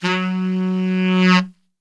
F#1 SAXSWL.wav